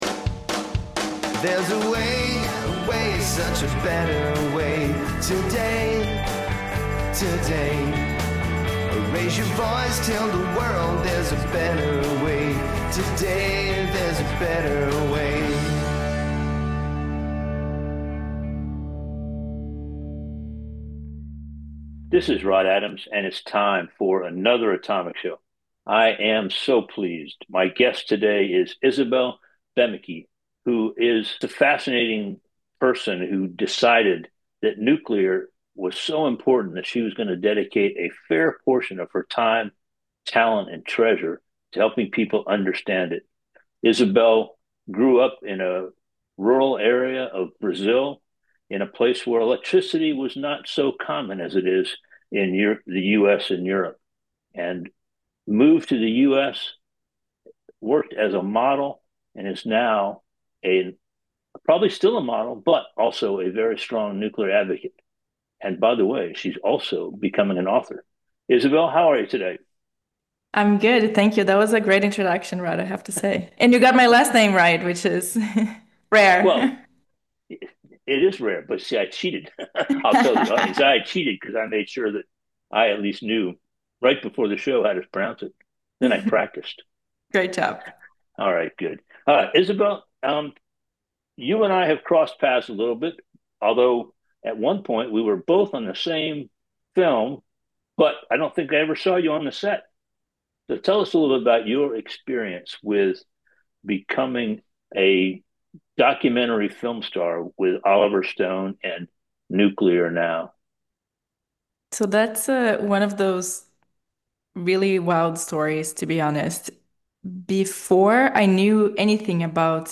The Atomic Show Podcast includes interviews, roundtable discussions and atomic geeks all centered around the idea that nuclear energy is an amazing boon for human society.